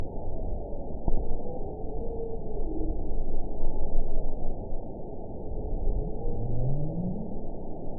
event 921107 date 04/28/24 time 16:52:42 GMT (1 year, 1 month ago) score 9.51 location TSS-AB05 detected by nrw target species NRW annotations +NRW Spectrogram: Frequency (kHz) vs. Time (s) audio not available .wav